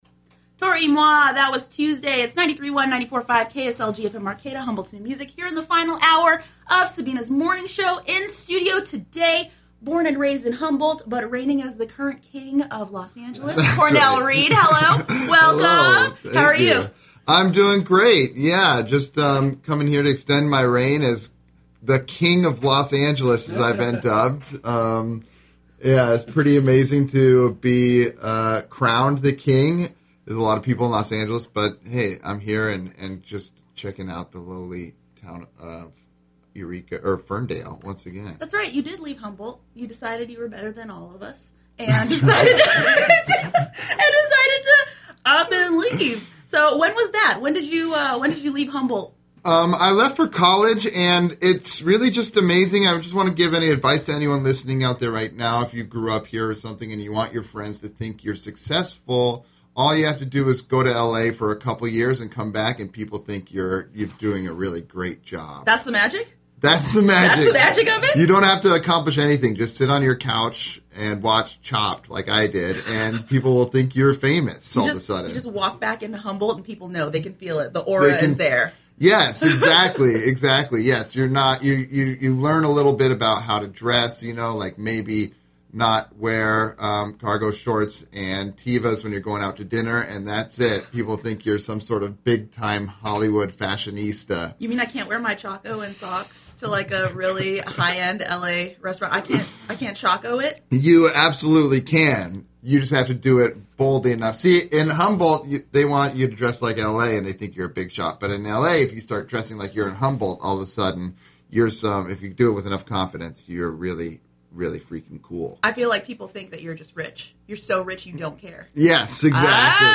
KSLG Interviews